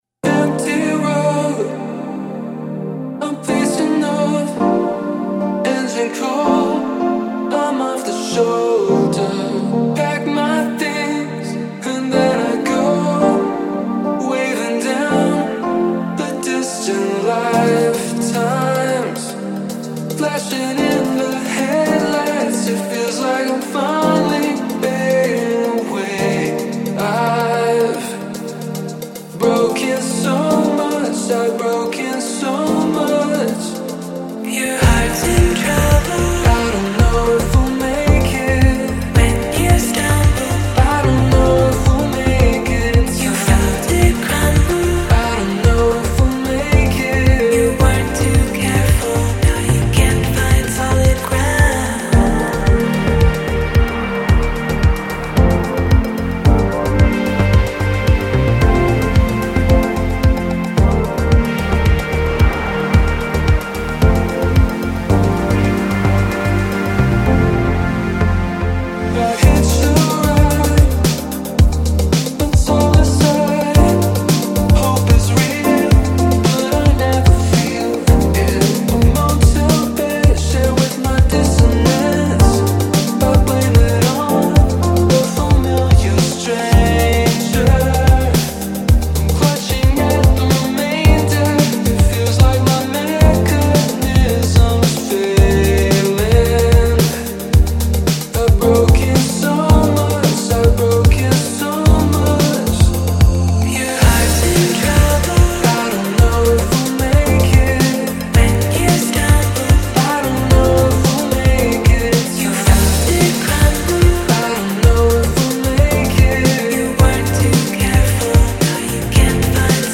synthpop boy/girl duo